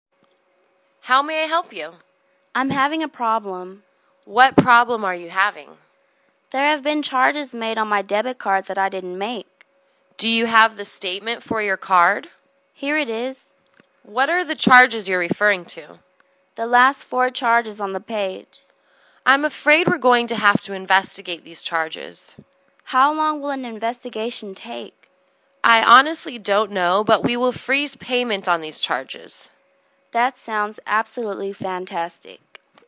银行英语对话-Reporting Charges(2) 听力文件下载—在线英语听力室